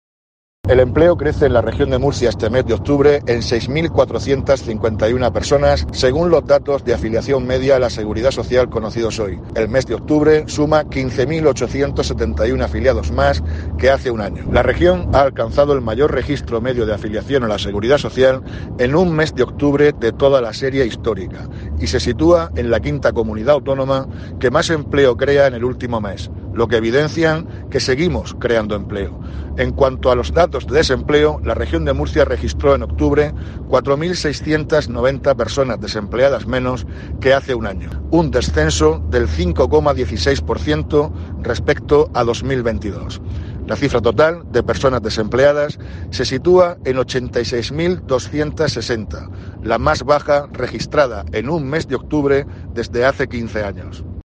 Víctor Marín, consejero de Empleo